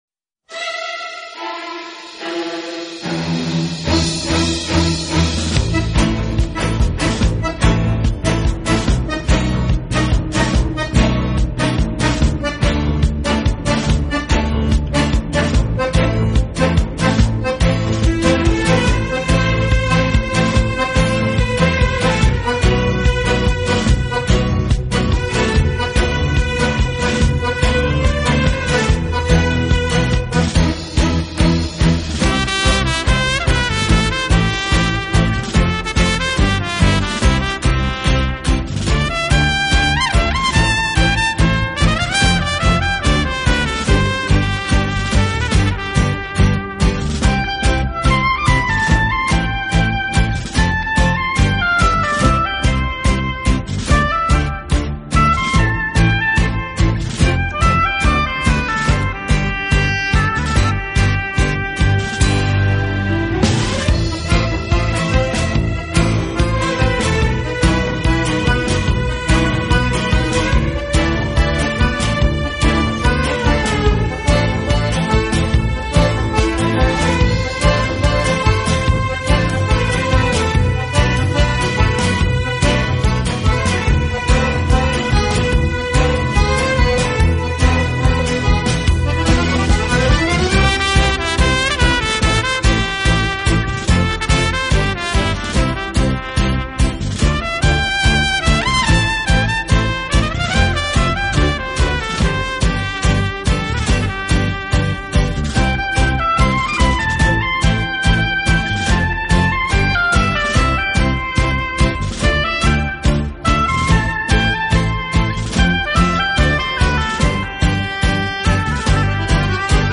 音乐类型: 轻音乐
唯美风格，华音曼妙，激情澎湃。
相嫁接，在中国第一次以"管弦乐队＋流行乐队＋特色乐器"的形式，